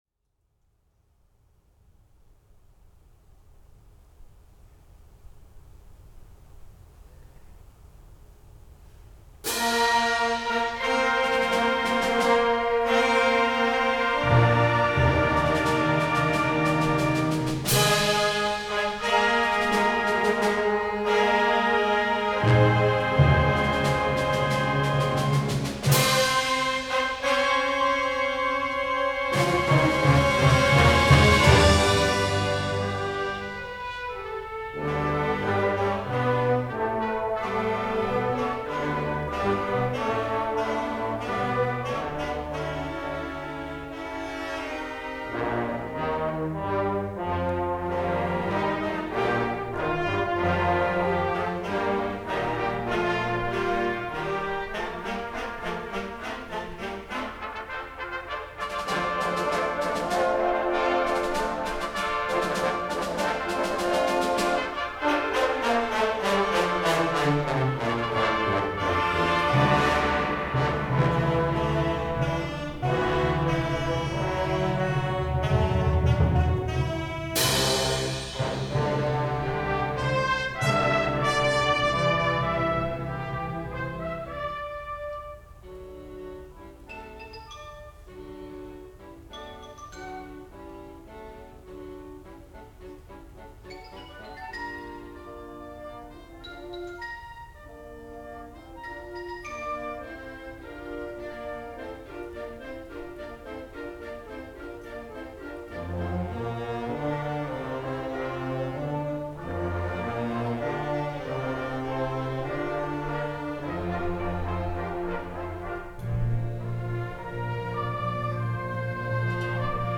The LBB's winter concert was on Sunday March 6th 2022 at the Lexington High School in Lexington.